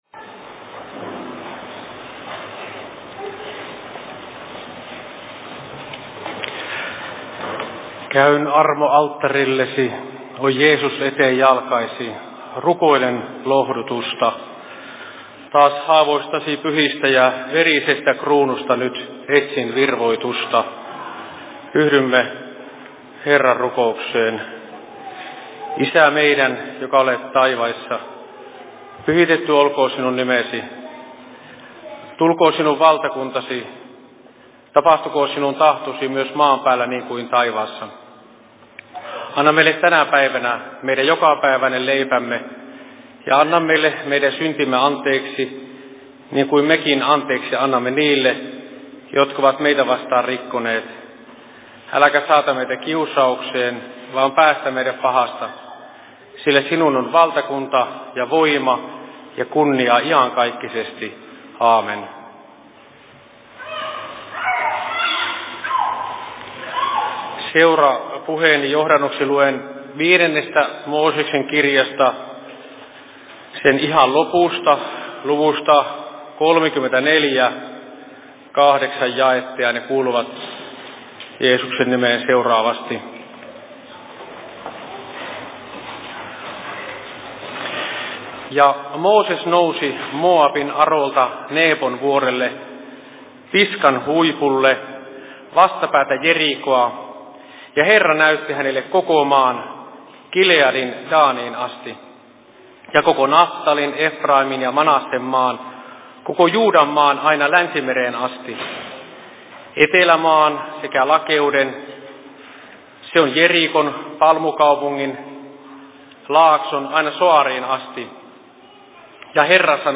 Seurapuhe Kajaanin RY:llä 22.08.2021 16.55